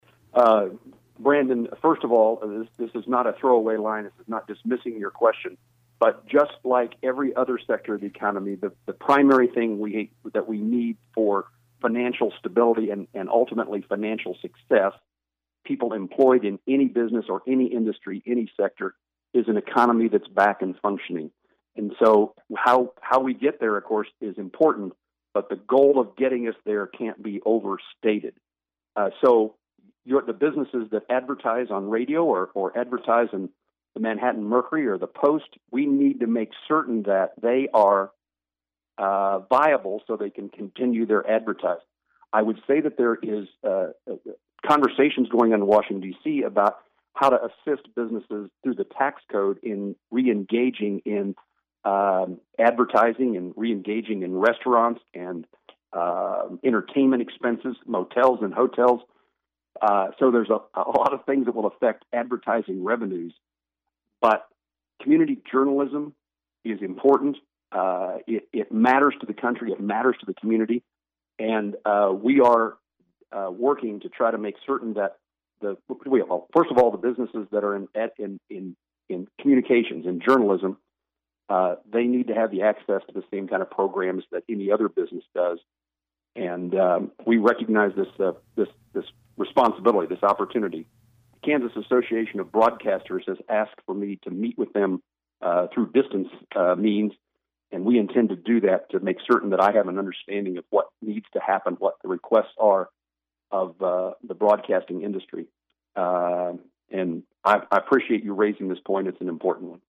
COVID-19 Q & A with U.S. Senator Jerry Moran